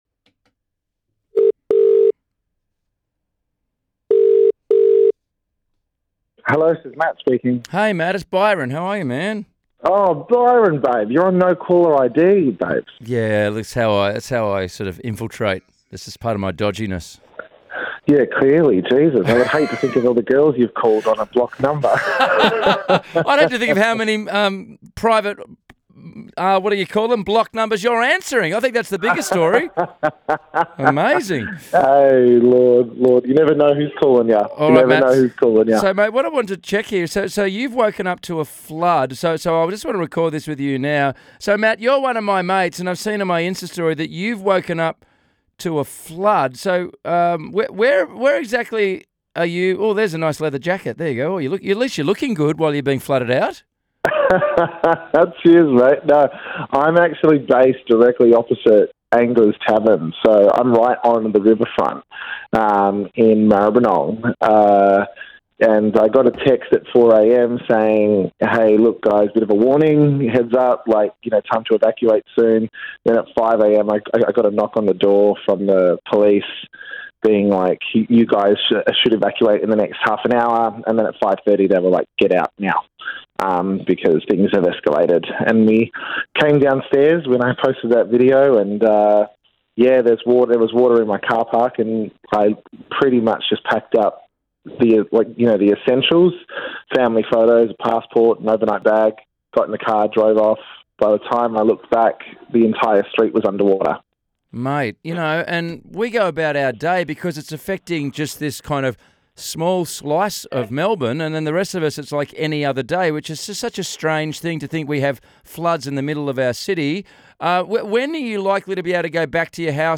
talked to him about the situation down there